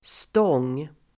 Uttal: [stång:]